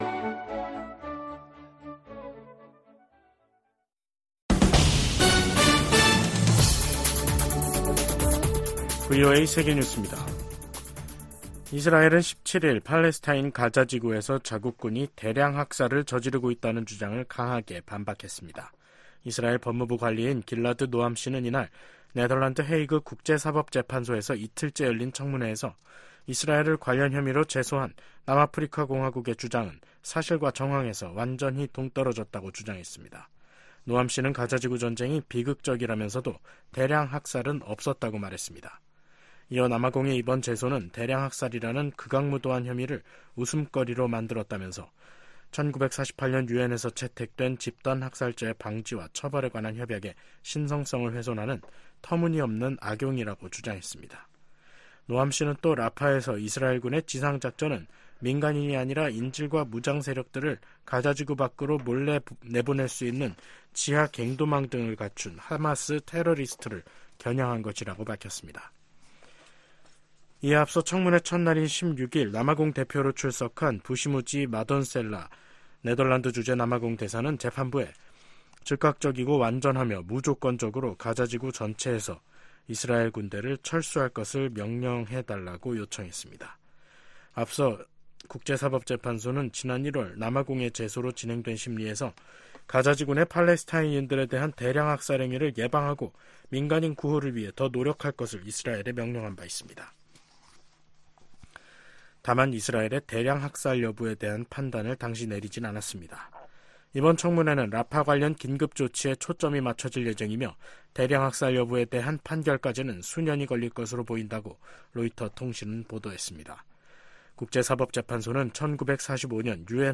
VOA 한국어 간판 뉴스 프로그램 '뉴스 투데이', 2024년 5월 17일 3부 방송입니다. 북한이 오늘 동해상으로 단거리 미사일 여러 발을 발사했습니다.